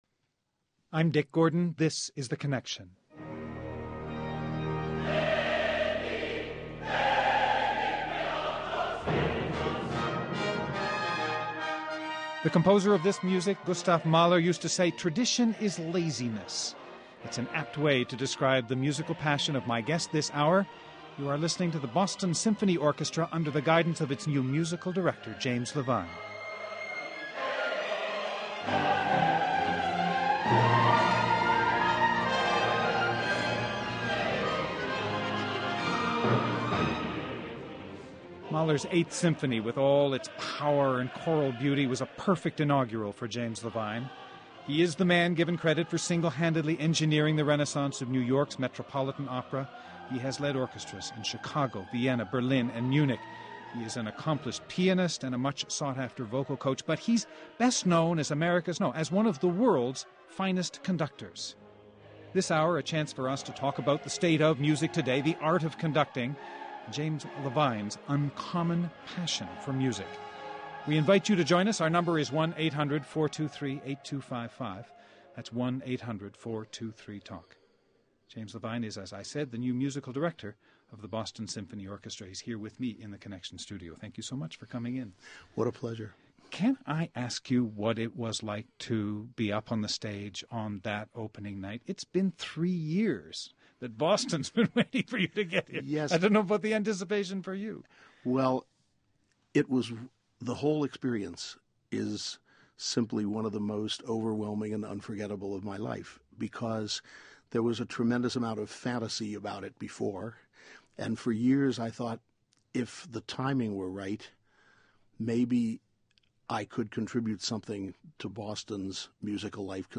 Guests: James Levine, 14th Musical Director of the Boston Symphony Orchestra.